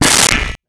Pot Lid 03
Pot Lid 03.wav